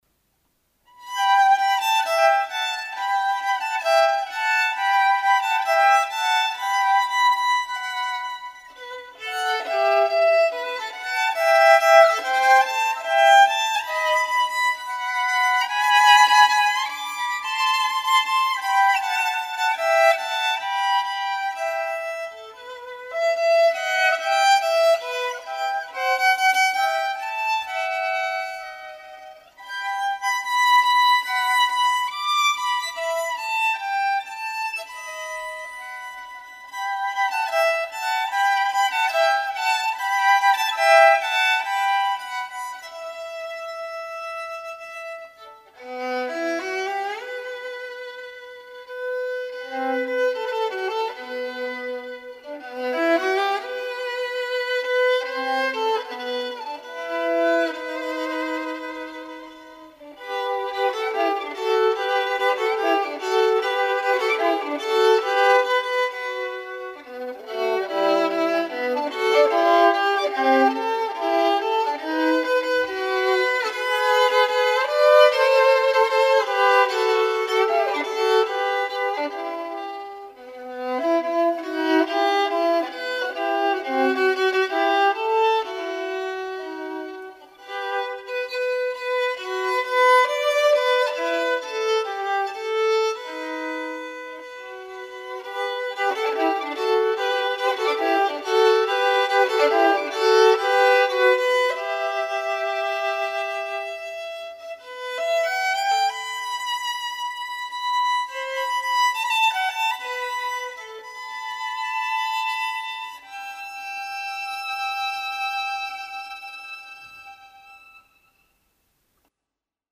今日はこの曲を弾いて秋を感じてみました。 The last Rose of summer「庭の千草」です。 アイルランドの民謡なのでアイリッシュミュージックを イメージして弾いてみました。 少しでもお楽しみ頂けましたらとても嬉しいです(^-^)m(_ _)m The last Rose of summer Irish Traditional : 庭の千草 / arre.